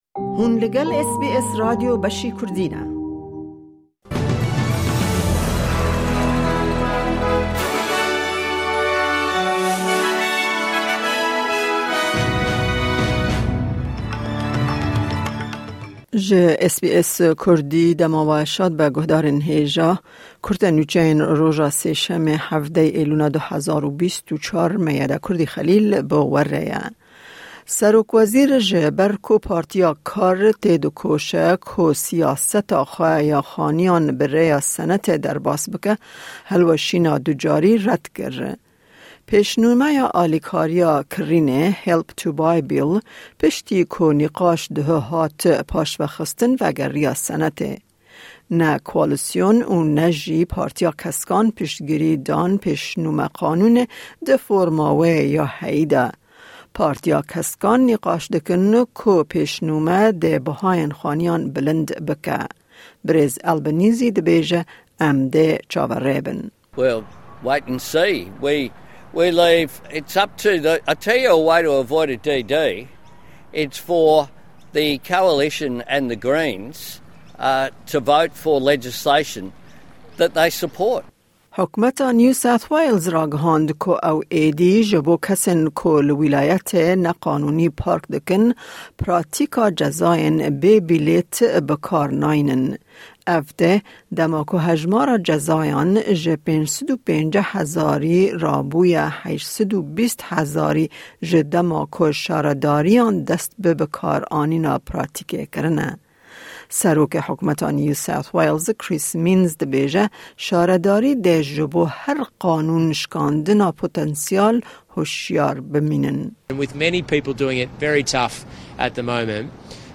Kurte Nûçeyên roja Sêşemê 17î Îlona 2024